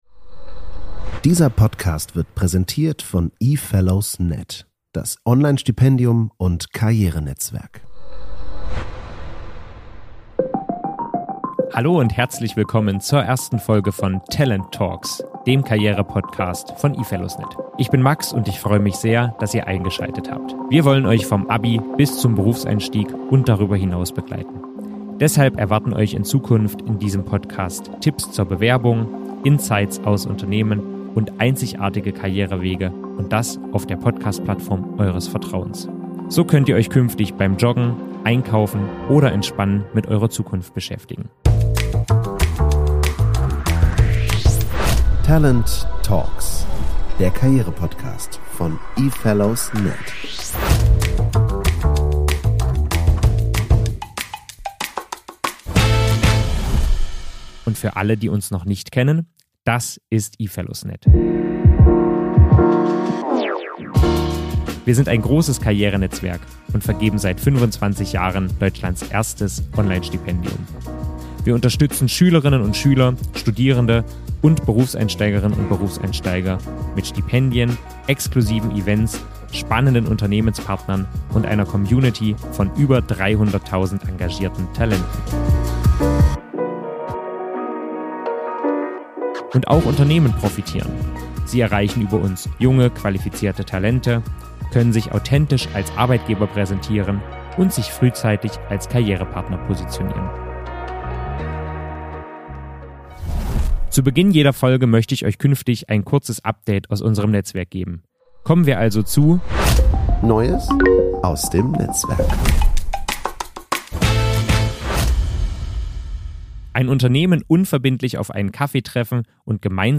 Egal, ob du Schüler:in, Student:in, Trainee oder (Young) Professional bist – bei TALENT Talks hörst du Interviews, die dich wirklich weiterbringen.